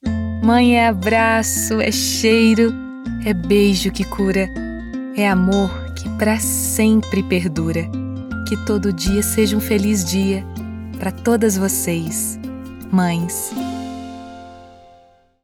Brazilian Portuguese voiceover Brazilian Portuguese emotional voice Brazilian Portuguese dubbing
Sprechprobe: Sonstiges (Muttersprache):
Emotional VO - Mother's day.mp3